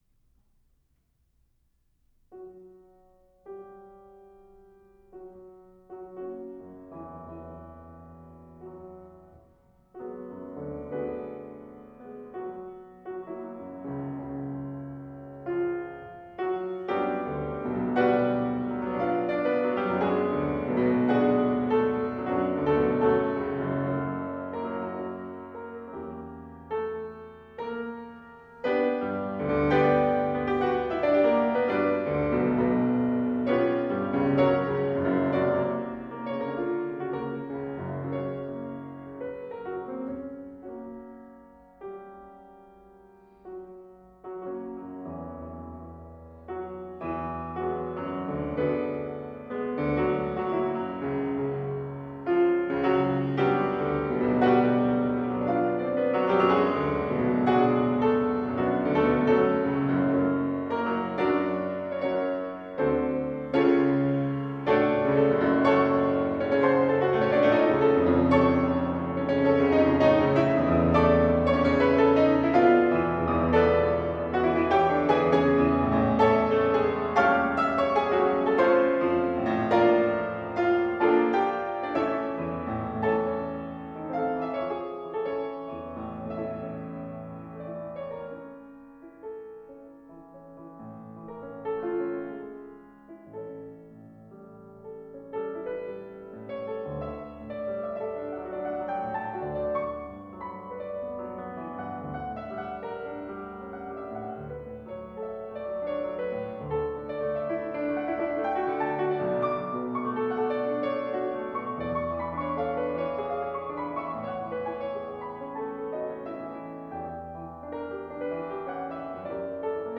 Solo